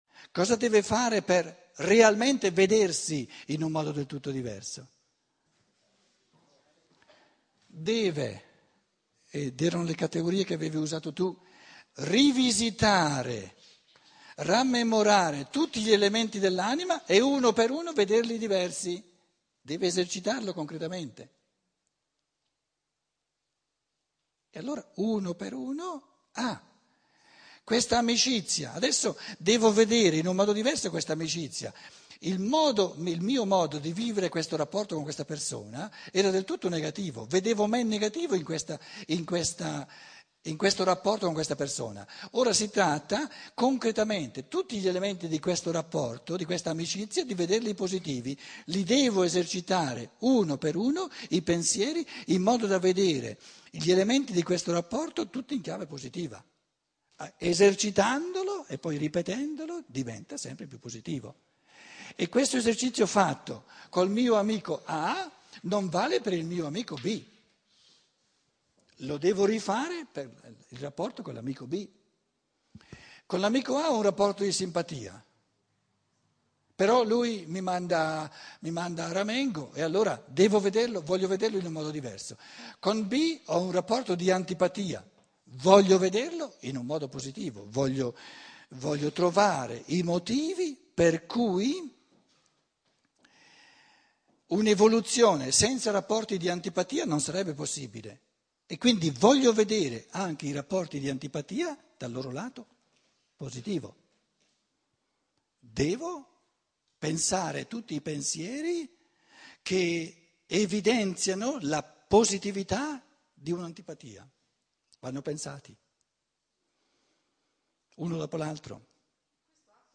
06F - Sesta conferenza - sabato pomeriggio